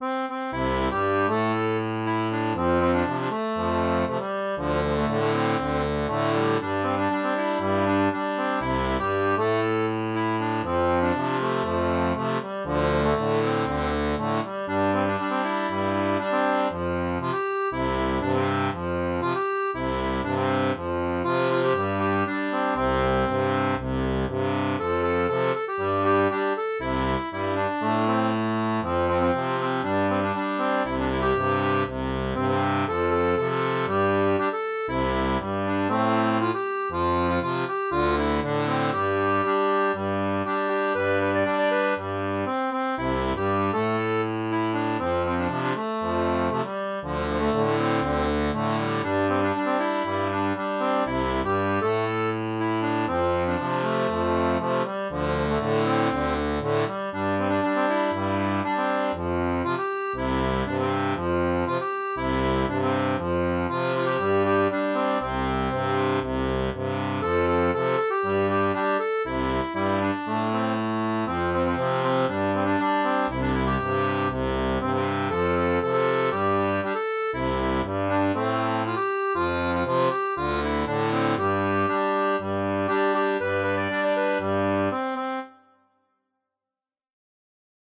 • une version transposée pour accordéon diatonique à 2 rangs
Chanson française